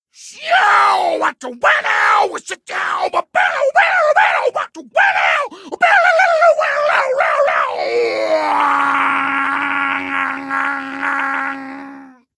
mouth-guitar_06
Category: Games   Right: Personal